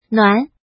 怎么读
nuán
nuan2.mp3